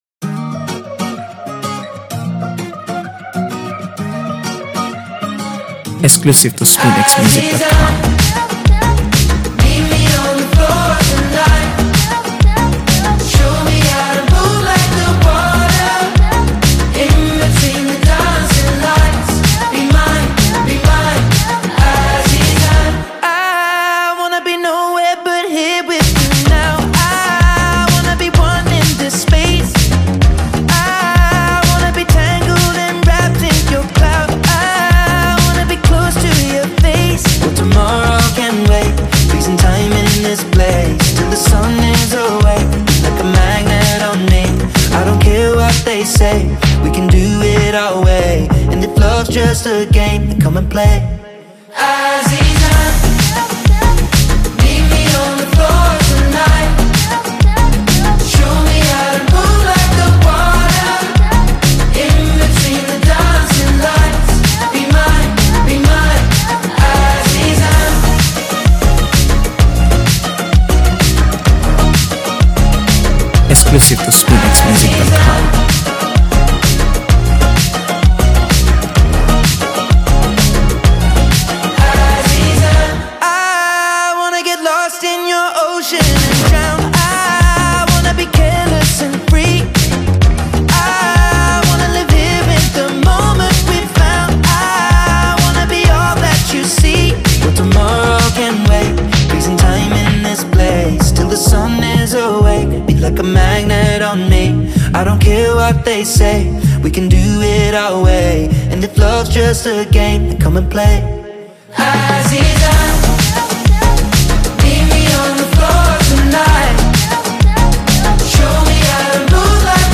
heartfelt new release